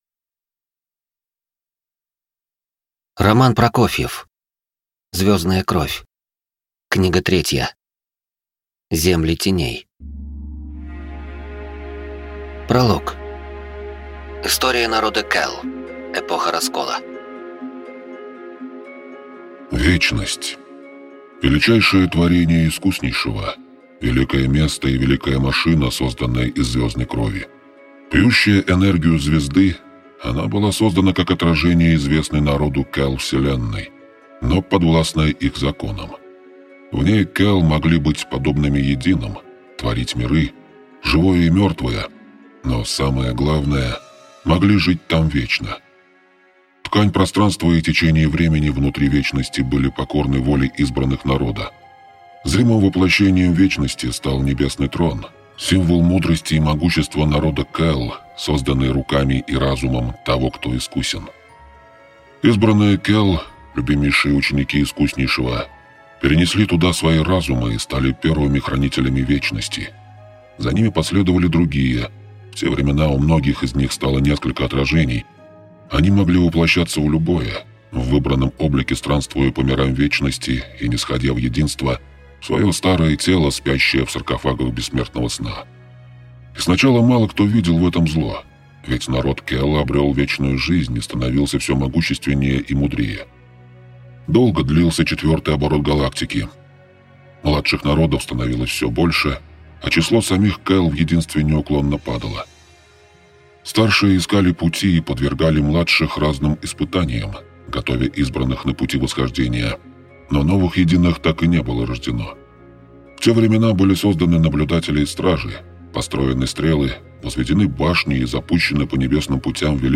Аудиокнига Звездная кровь-3. Земли теней | Библиотека аудиокниг